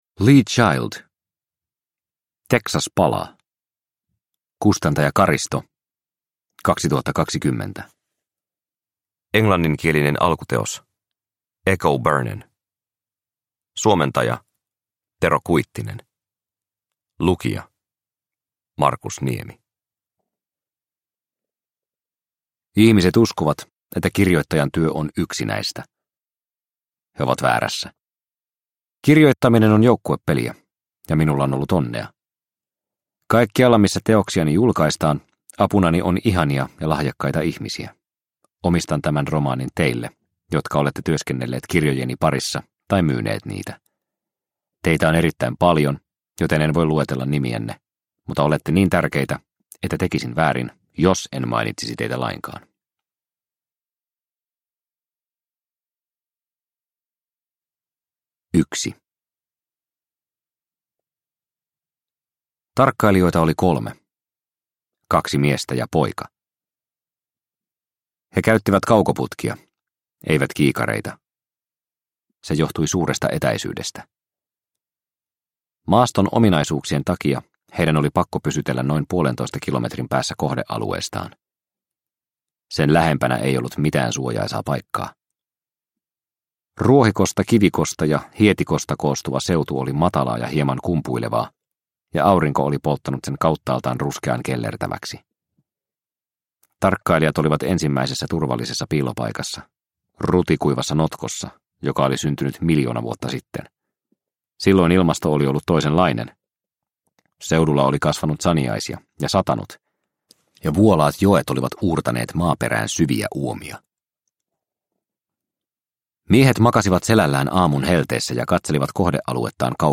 Teksas palaa – Ljudbok – Laddas ner